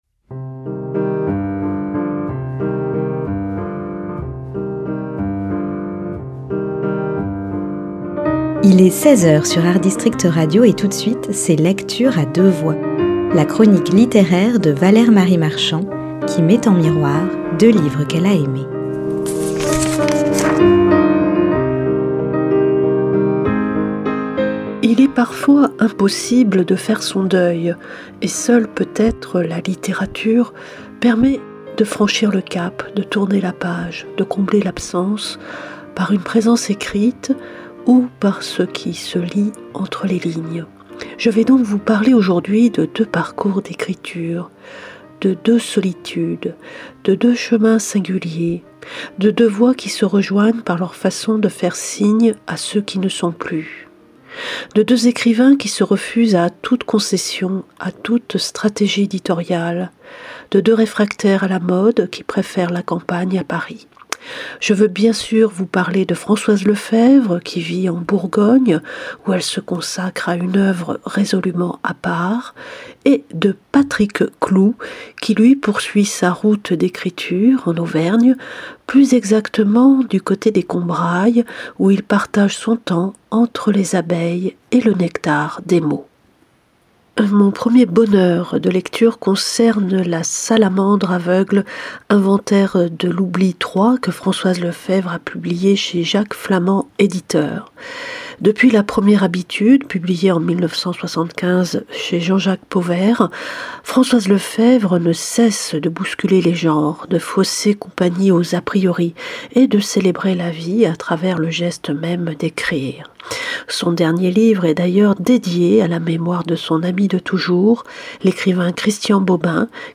LECTURE A DEUX VOIX, mardi et vendredi à 10h et 16h.